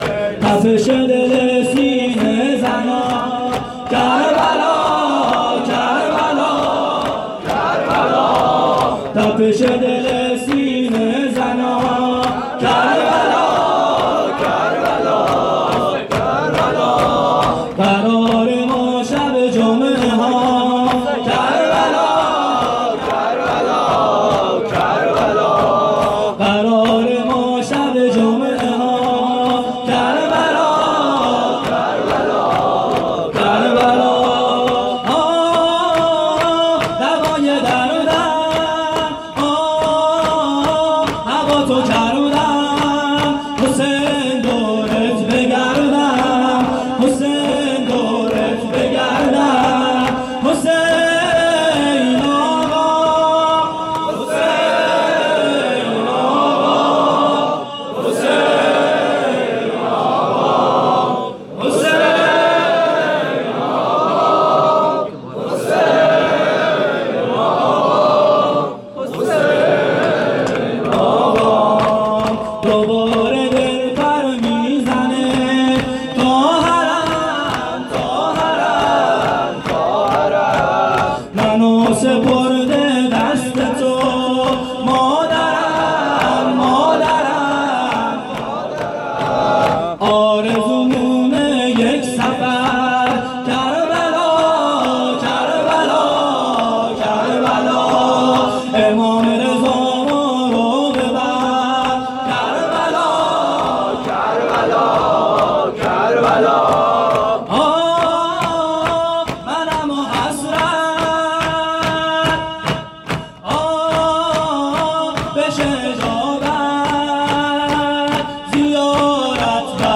شب اول محرم ۹۷ هیئت صادقیون(ع)